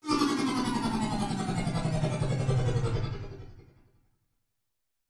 SouthSide Trap Transition (21).wav